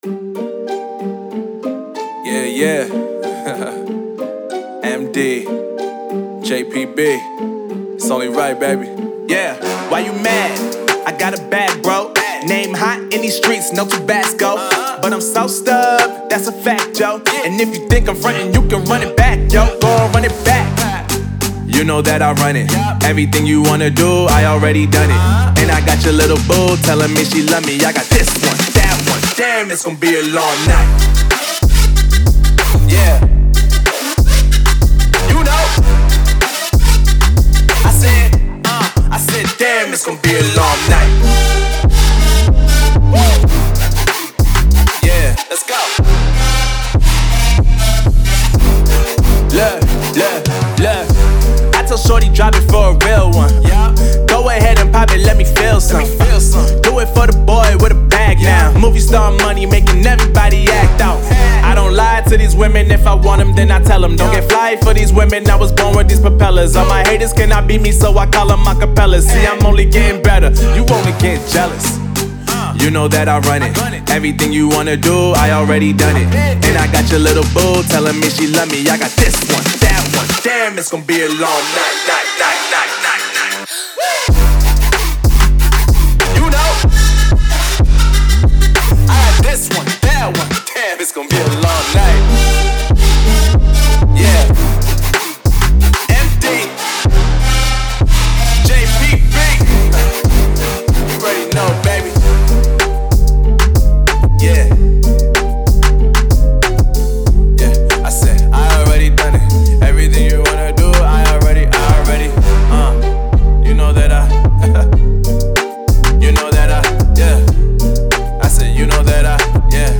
Trap, Energetic, Angry, Epic, Euphoric, Gloomy